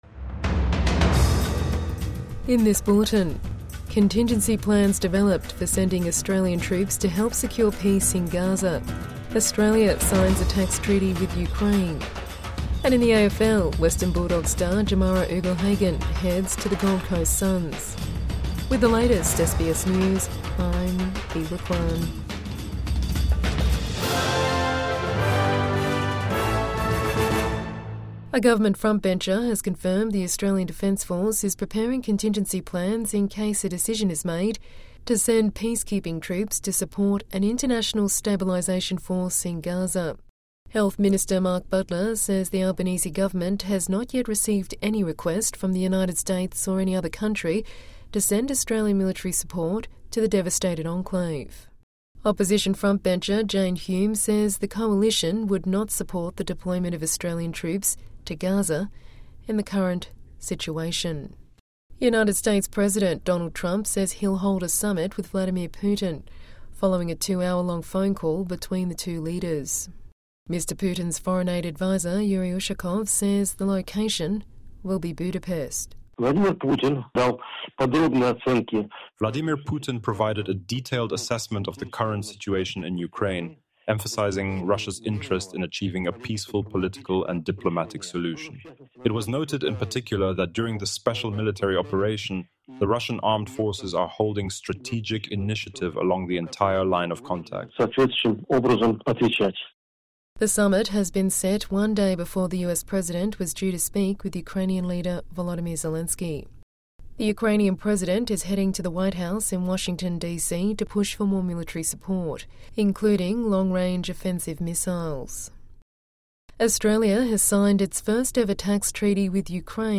Plan developed for possible Australian troop deployment to Gaza | Midday News Bulletin 17 October 2025